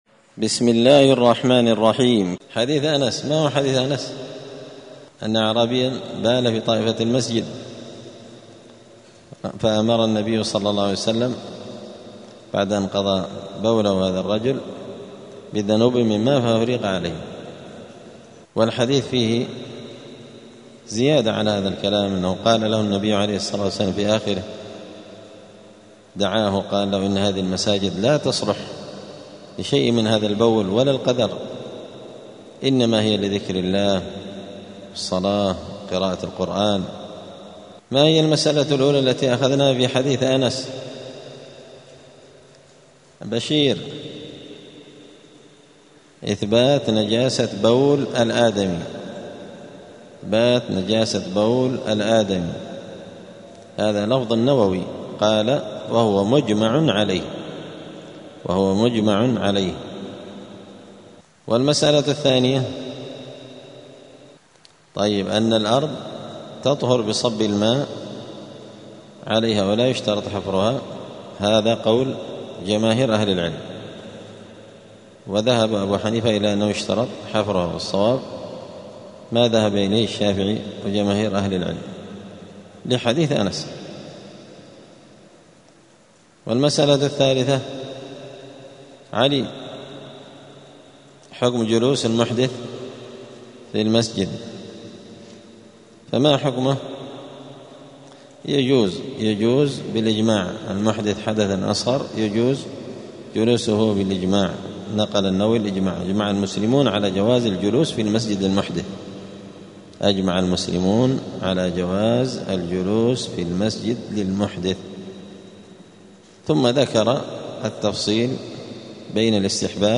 دار الحديث السلفية بمسجد الفرقان قشن المهرة اليمن
*الدرس السابع بعد المائة [107] {باب إزالة النجاسة حكم بول وروث الحيوانات}*